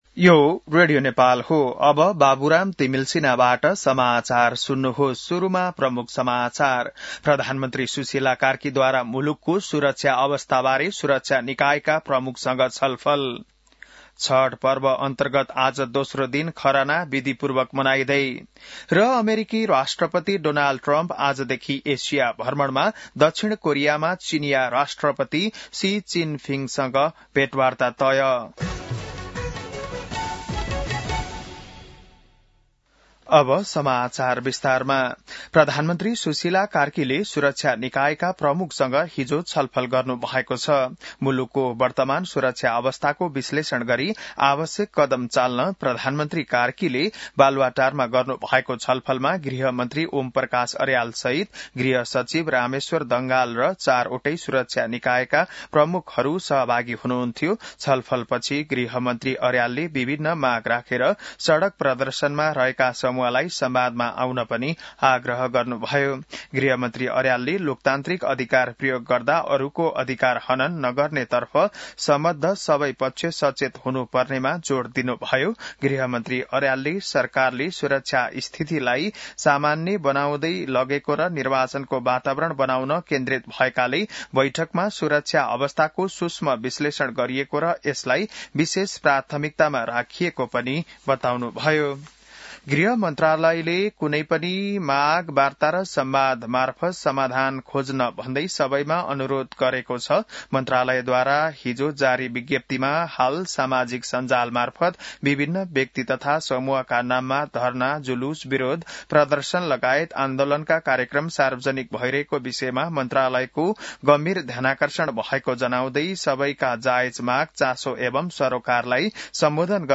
An online outlet of Nepal's national radio broadcaster
बिहान ९ बजेको नेपाली समाचार : १८ पुष , २०२६